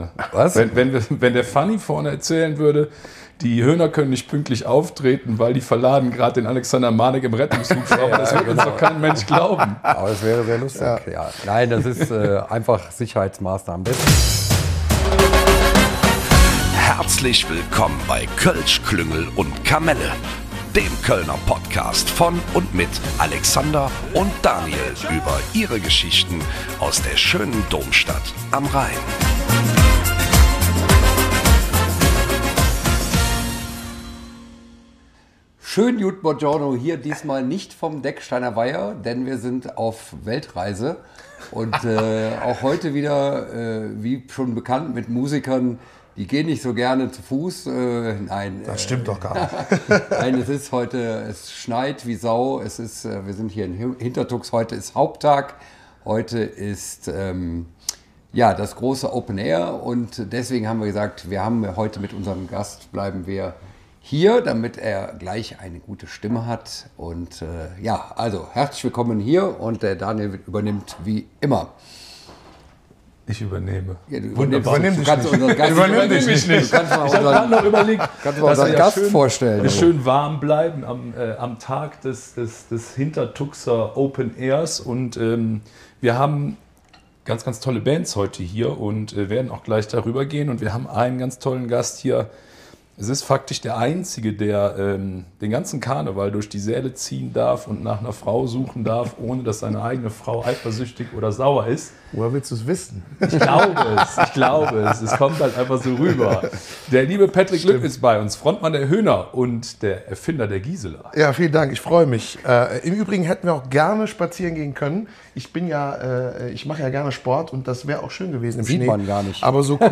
Euch erwartet eine unterhaltsamer Podcast - über Prinzessinnen, Giselas und Rettungshubschrauber - aus dem massiv verschneiten Hintertux während der kölschen Woche.